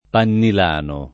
pannilano [ pannil # no ]